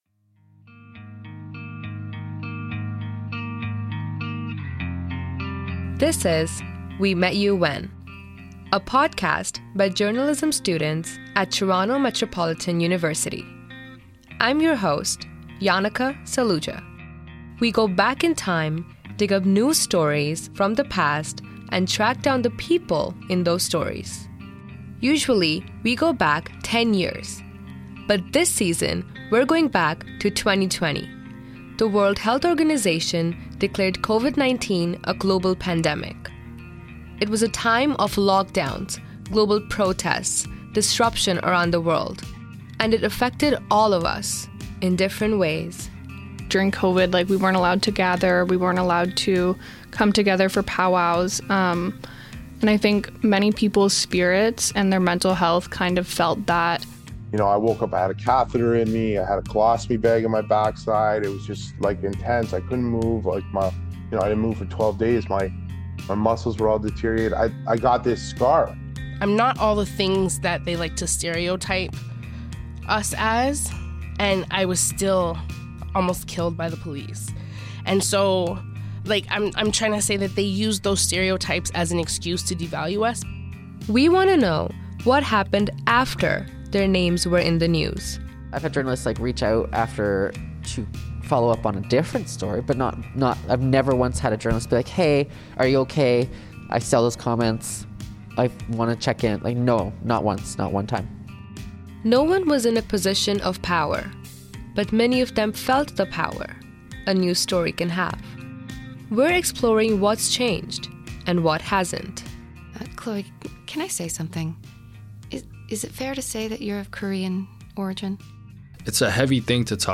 Acoustic Guitars Ambient Uplifting Background Music for Videos
Powwow Sounds
This was a live recording 2.